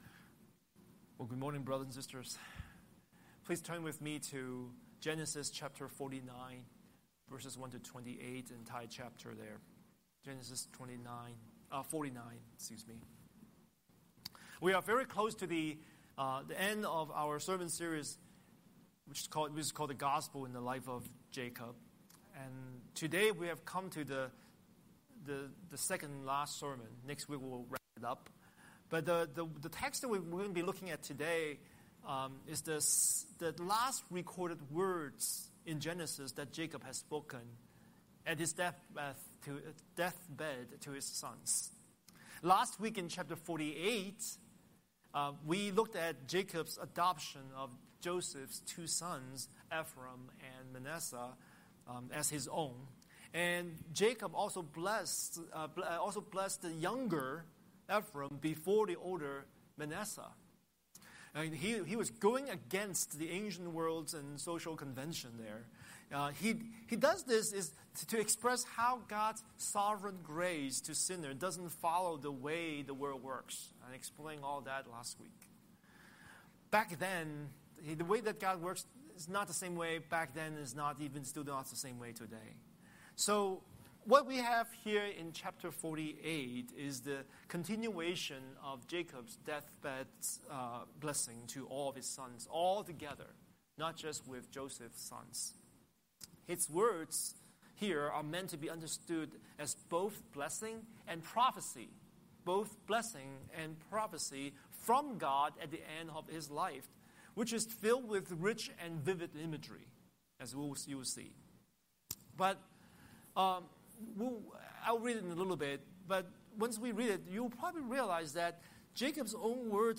Scripture: Genesis 49:1–28 Series: Sunday Sermon